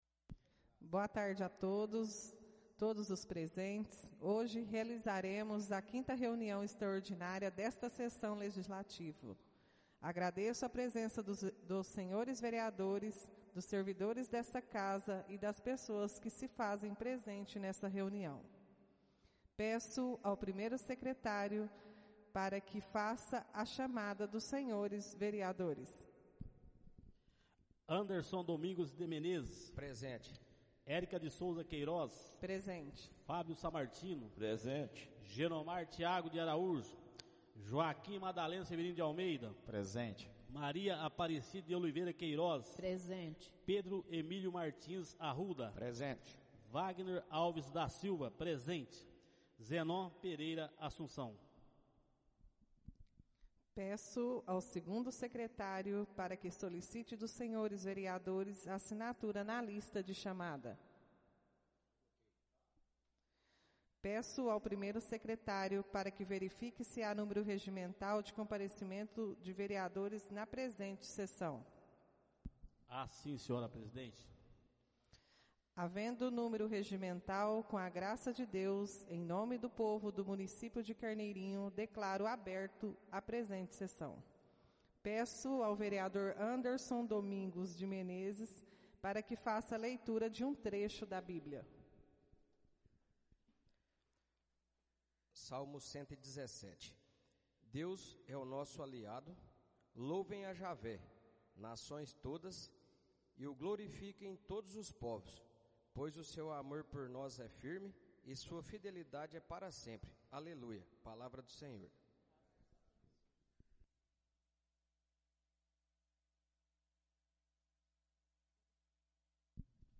Áudio da 05.ª reunião extraordinária de 2022, realizada no dia 28 de junho de 2022, na sala de sessões da Câmara Municipal de Carneirinho, Estado de Minas Gerais.